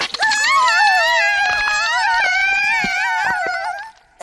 Yamete-Kudasai - 😖 Cute anime girls moaning when something is plugged in